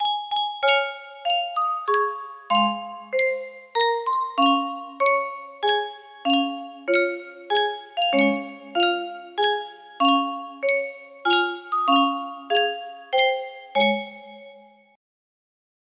Yunsheng Music Box - The Star-Spangled Banner Y533 music box melody
Full range 60